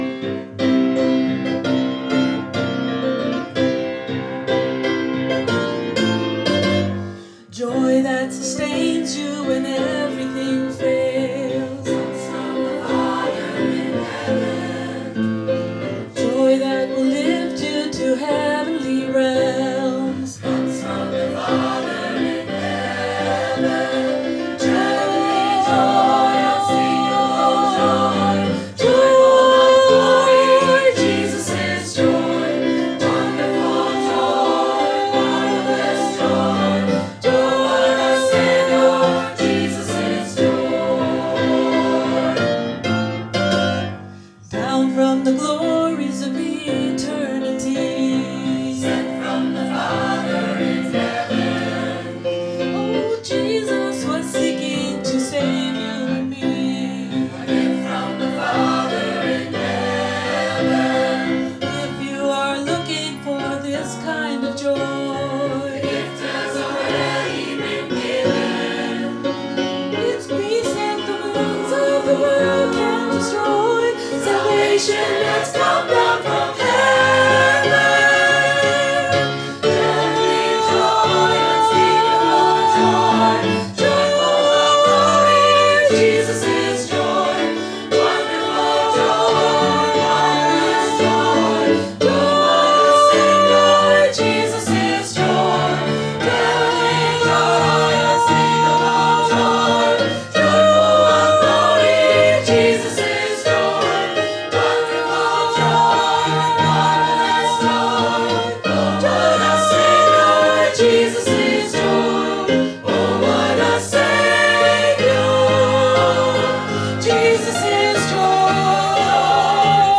Adult Choir – Joy
Choir-Joy.wav